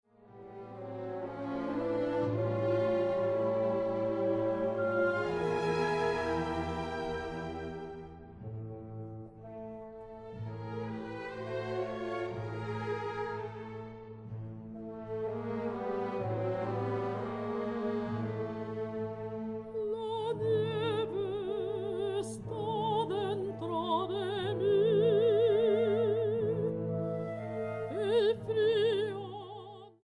Grabado en: Teatro Aguascalientes, marzo, 2013.